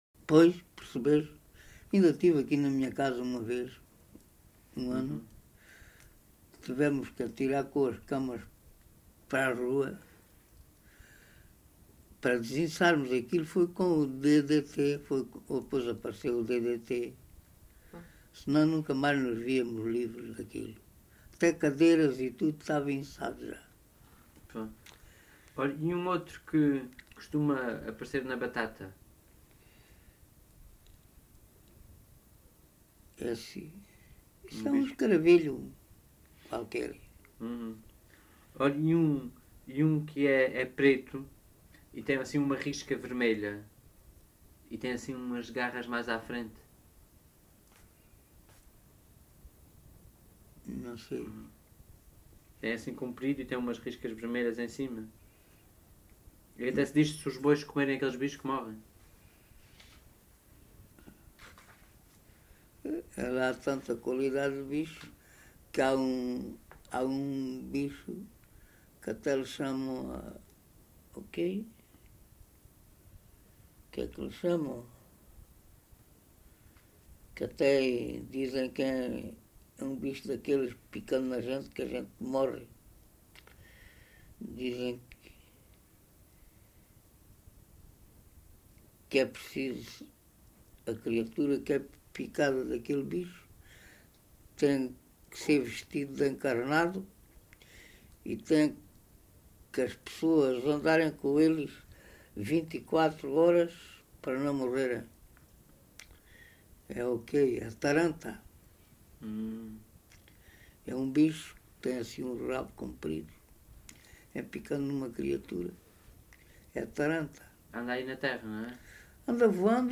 LocalidadeCarrapatelo (Reguengos de Monsaraz, Évora)